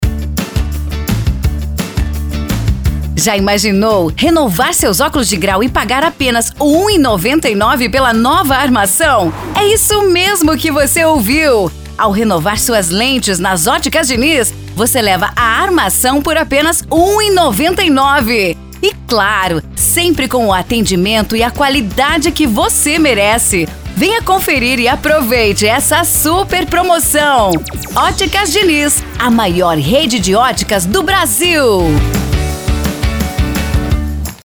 Estilo Animado: